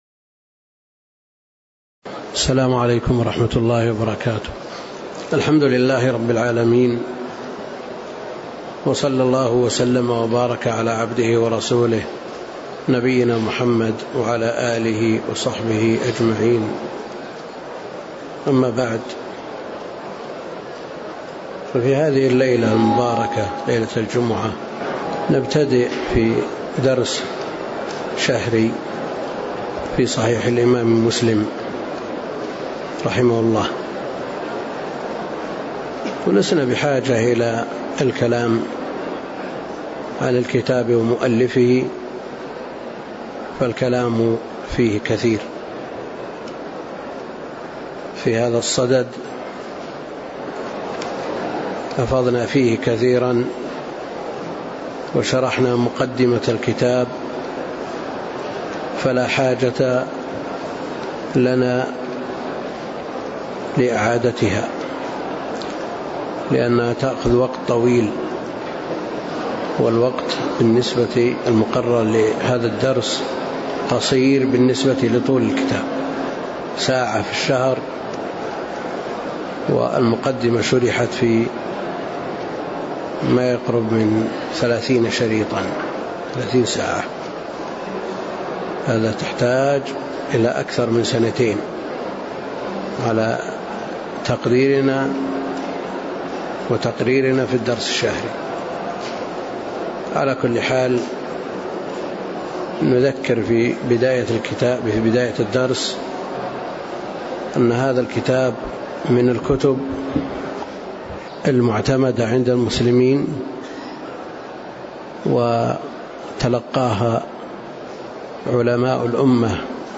تاريخ النشر ٢٦ شوال ١٤٣٣ المكان: المسجد النبوي الشيخ: فضيلة الشيخ د. عبد الكريم بن عبد الله الخضير فضيلة الشيخ د. عبد الكريم بن عبد الله الخضير 01المقدمة